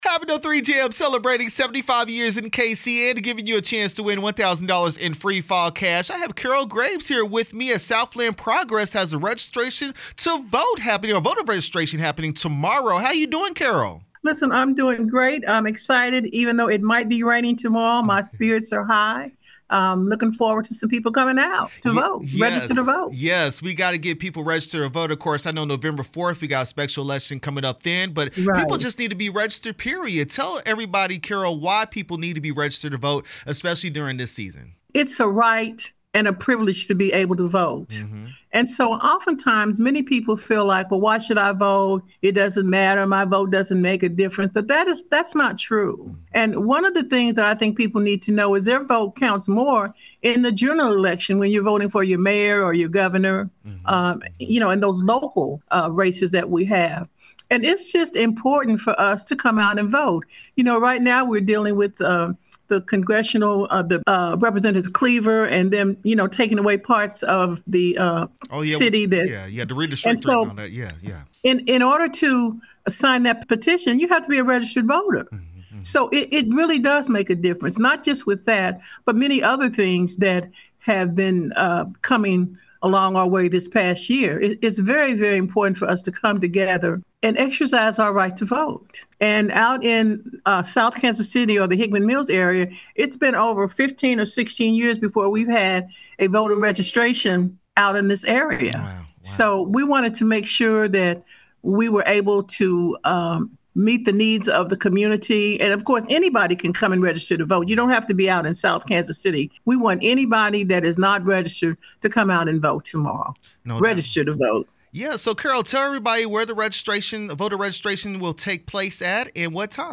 Southland Progress Voter Registration Drive interview 10/24/25